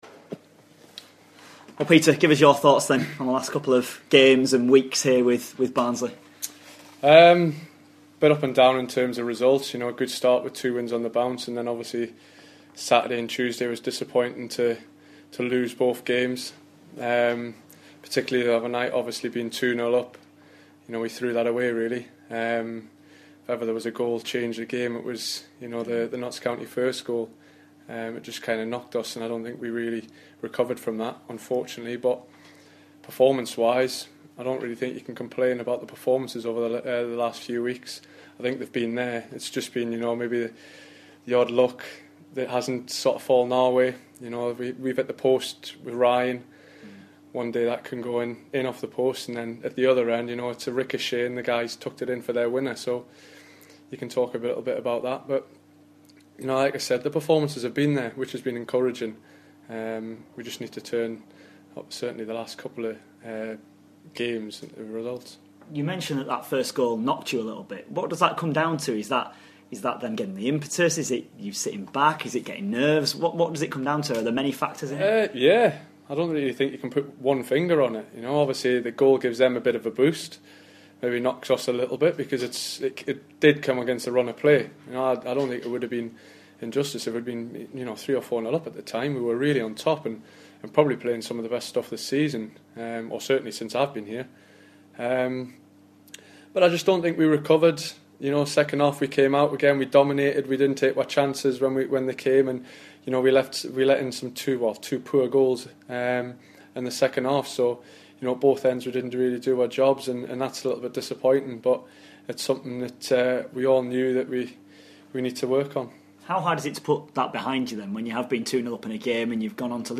INTERVIEW: Barnsley defender Peter Ramage ahead of the Reds game with Bristol City.